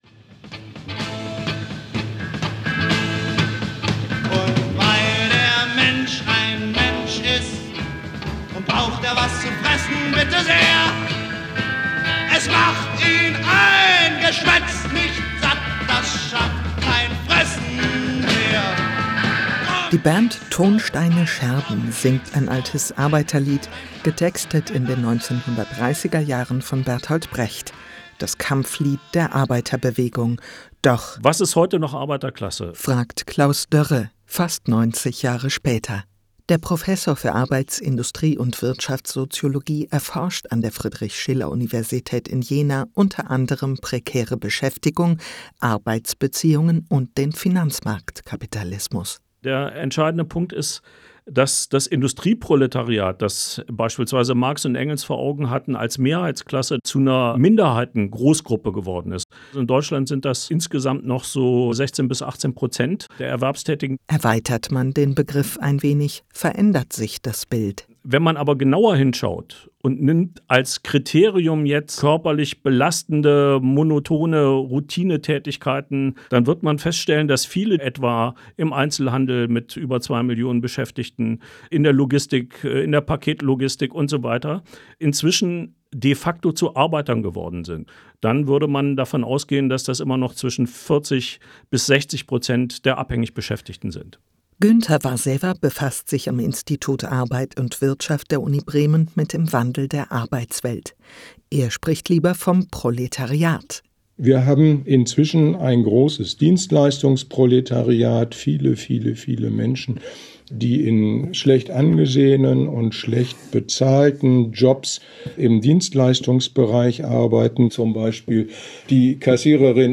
Medienlinks / media links Radiobeiträge
Dazu habe ich im Dezember 2021 mit Wissenschaftler*innen, Gewerkschafter*innen gesprochen.